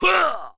文件 文件历史 文件用途 全域文件用途 Enjo_atk_01_3.ogg （Ogg Vorbis声音文件，长度0.6秒，146 kbps，文件大小：10 KB） 源地址:地下城与勇士游戏语音 文件历史 点击某个日期/时间查看对应时刻的文件。